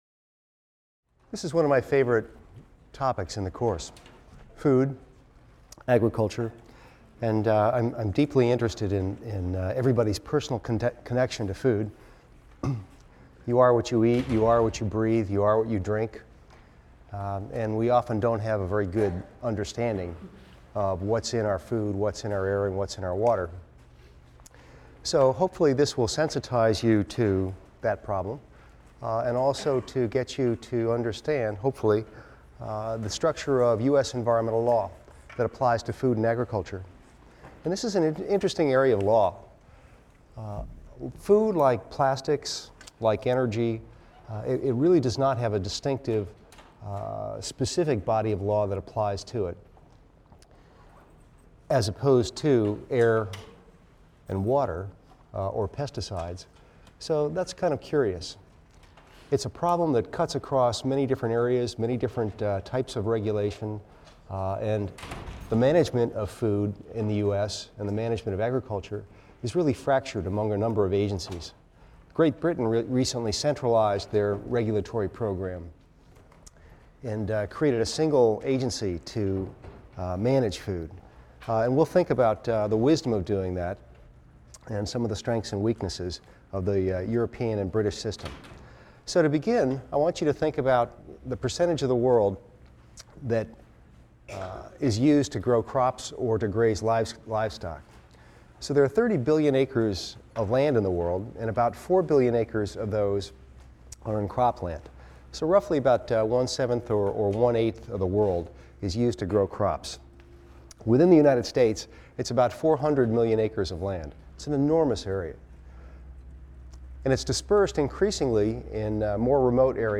EVST 255 - Lecture 8 - Chemically Dependent Agriculture | Open Yale Courses